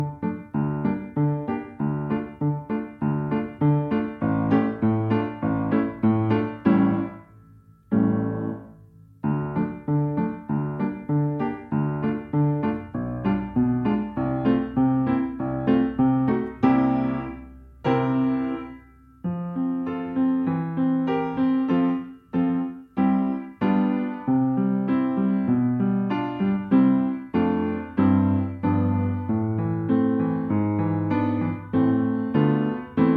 Nagranie bez metronomu, uwzględnia rubata.
Allegro moderato II: 88 bmp
Nagranie dokonane na pianinie Yamaha P2, strój 440Hz